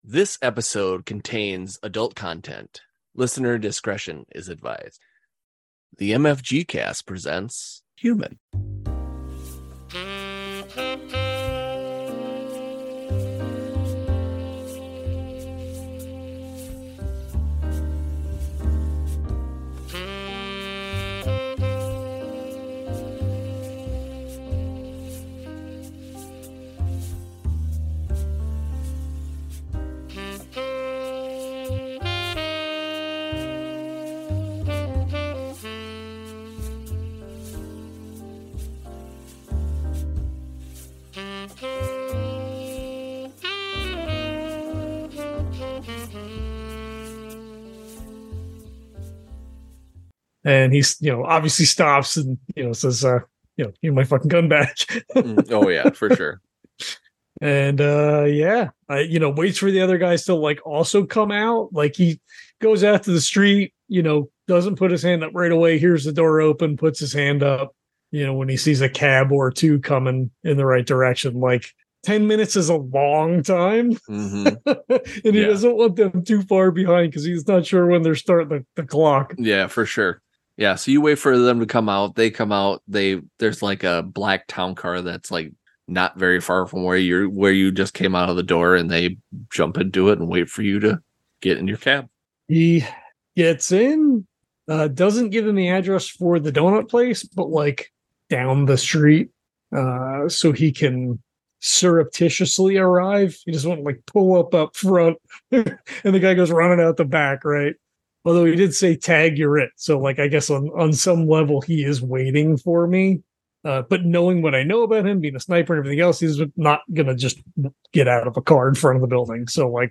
Actual Play